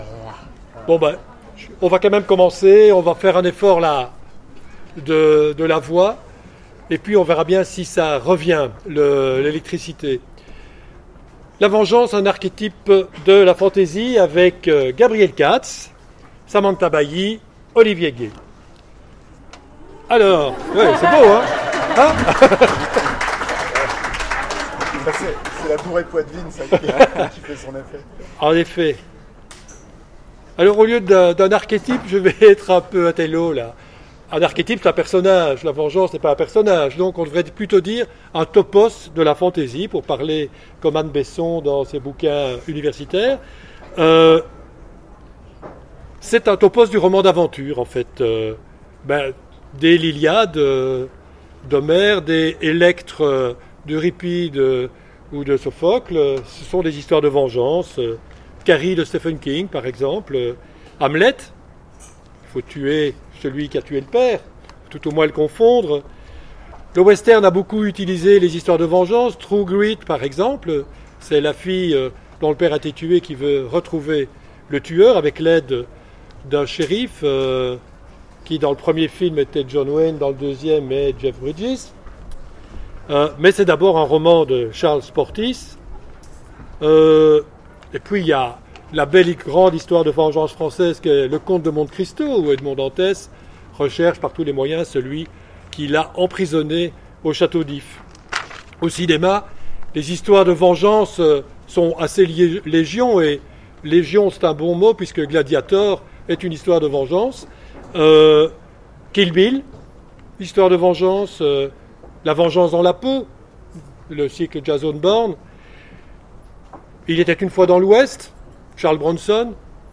Imaginales 2016 : Conférence La vengeance…
- le 31/10/2017 Partager Commenter Imaginales 2016 : Conférence La vengeance…